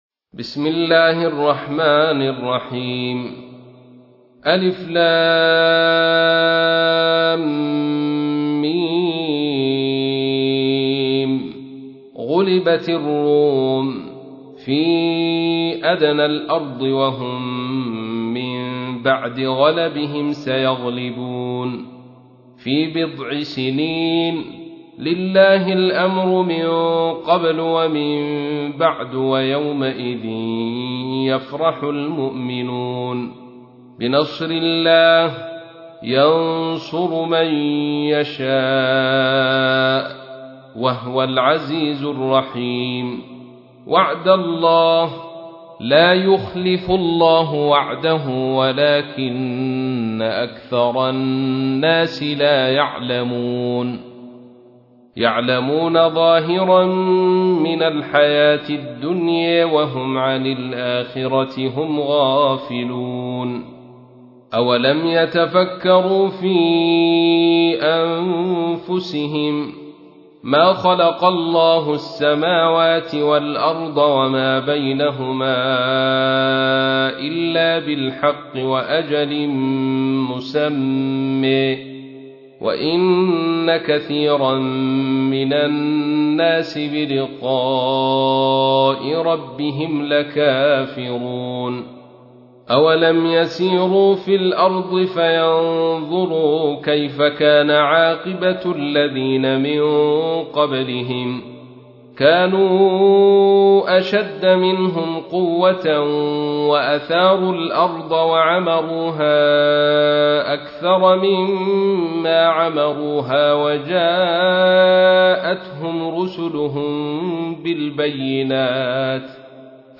تحميل : 30. سورة الروم / القارئ عبد الرشيد صوفي / القرآن الكريم / موقع يا حسين